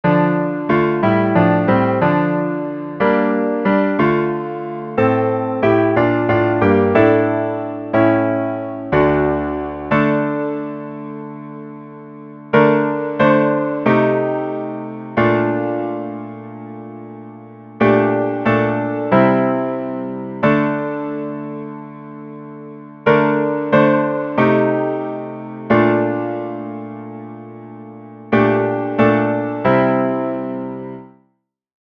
second alternative chant
(Verse tone in this mp3 follows refrain with no pause.)